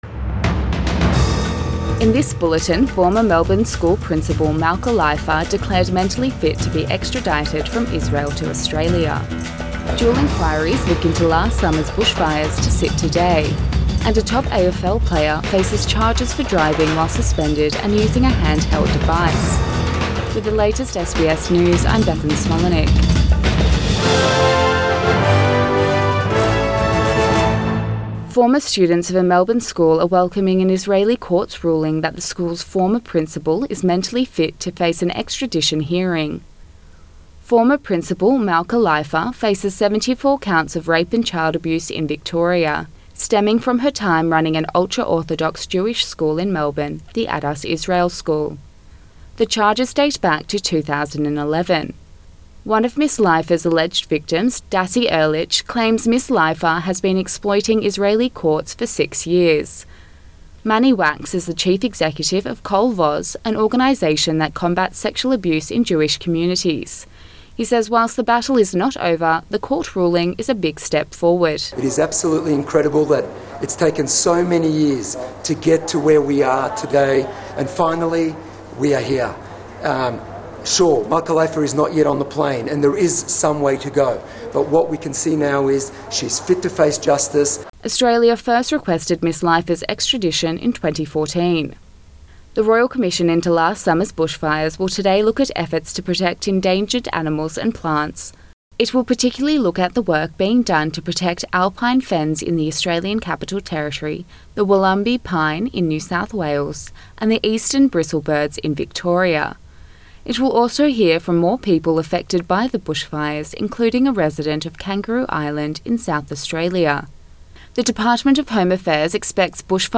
AM bulletin 27 May 2020